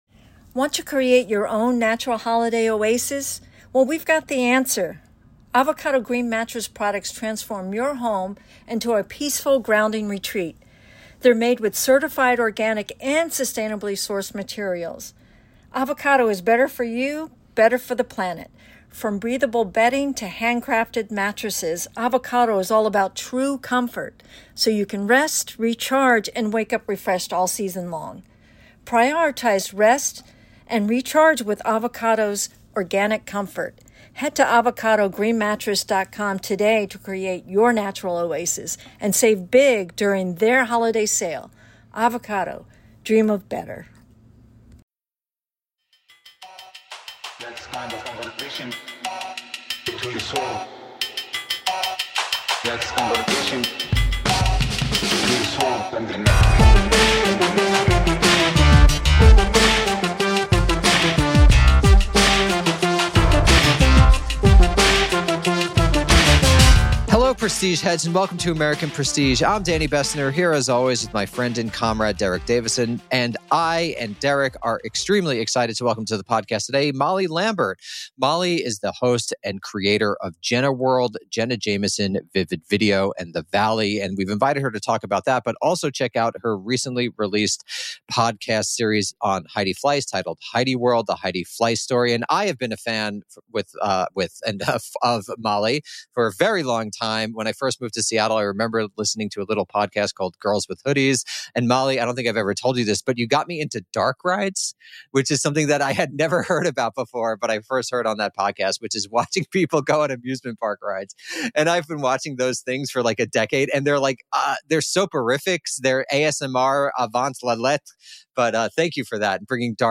1 Sleep expert Matthew Walker on snoring, napping, nightmares and the power of slumber 51:53 Play Pause 27d ago 51:53 Play Pause Play later Play later Lists Like Liked 51:53 In this episode, we speak with Matthew Walker.